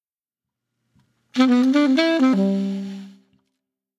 Джазовый Brass сэмпл: Latin feel (Jazz Sample)
Тут вы можете прослушать онлайн и скачать бесплатно аудио запись из категории «Jazz (Джаз)».